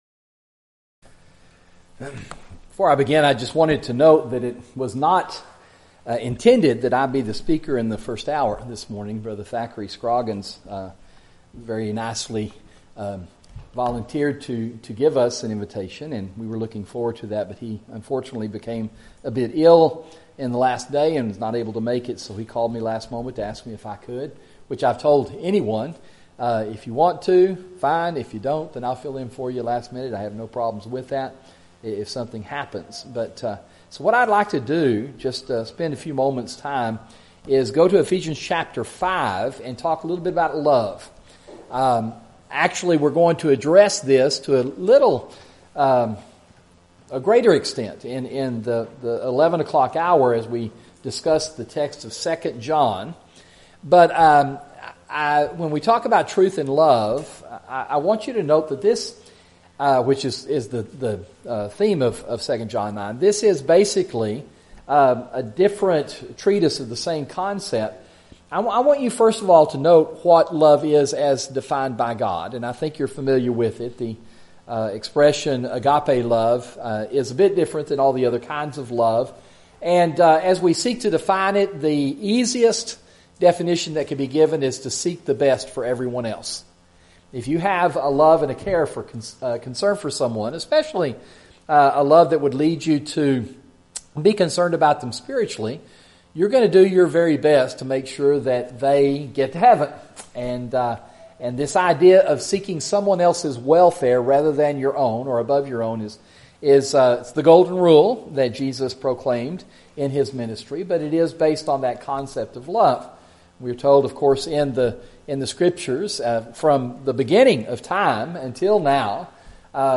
Audio YouTube Video of Sermon Share this: Tweet